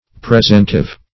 Presentive \Pre*sent"ive\, a. (Philol.)